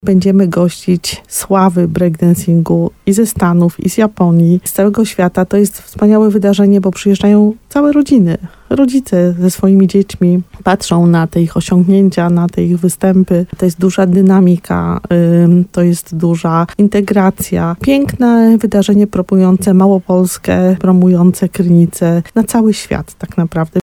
Jak powiedziała w programie Słowo za Słowo w radiu RDN Nowy Sącz